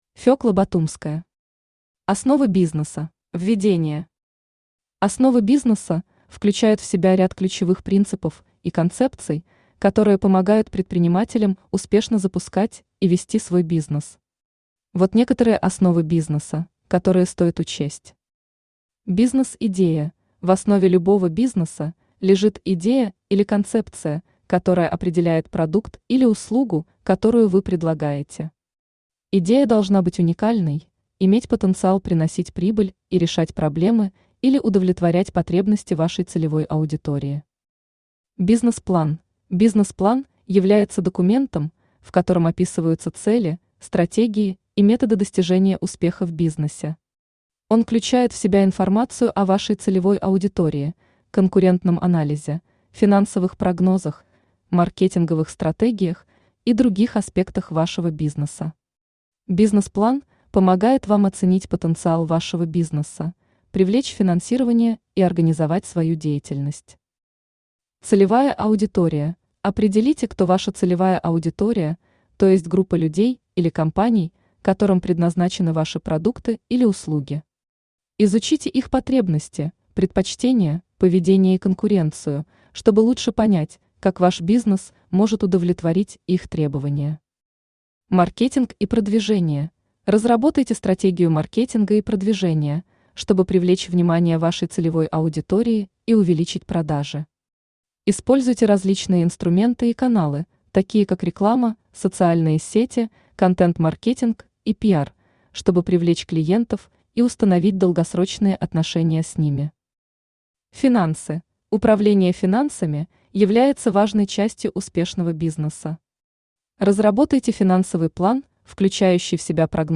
Аудиокнига Основы бизнеса | Библиотека аудиокниг
Aудиокнига Основы бизнеса Автор Фёкла Батумская Читает аудиокнигу Авточтец ЛитРес.